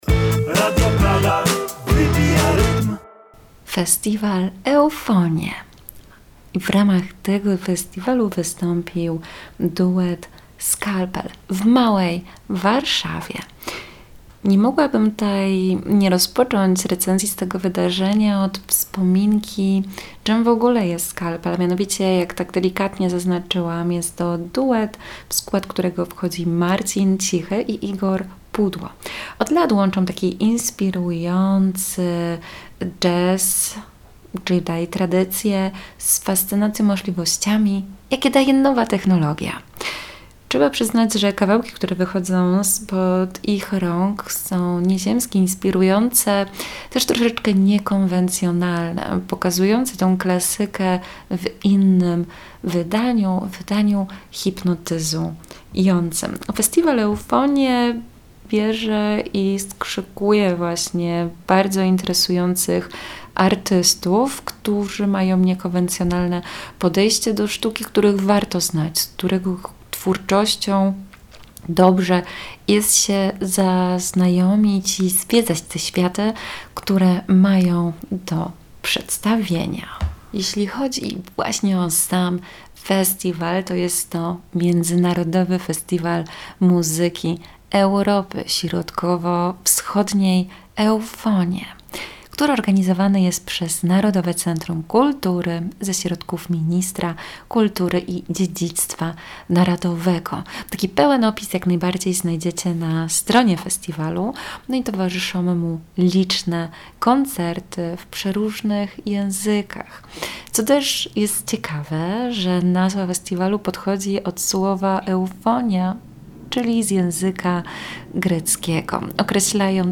Posłuchaj relacji z koncertu i przeżyj to jeszcze raz.